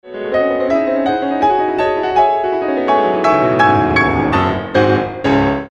poweron.mp3